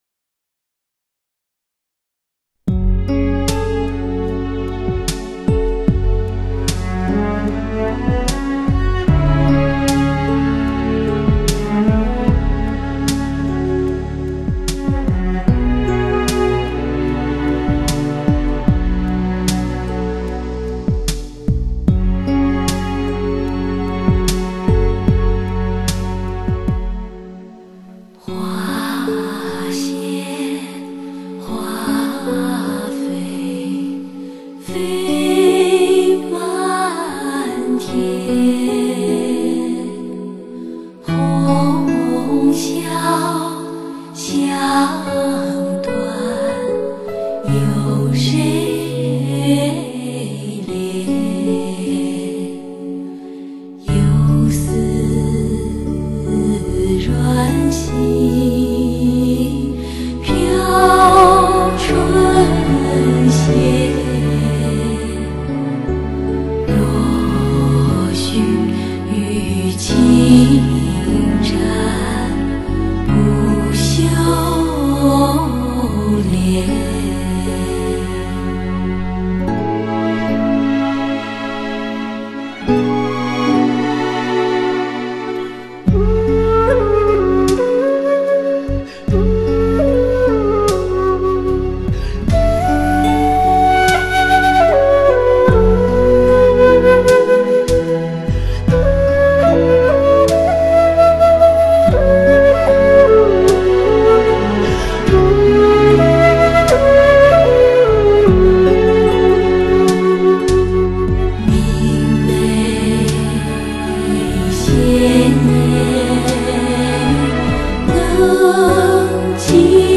只需聆听，不需言语，在极致的音色和顶级的环绕声效中，一切都回归到了最初感动的原点。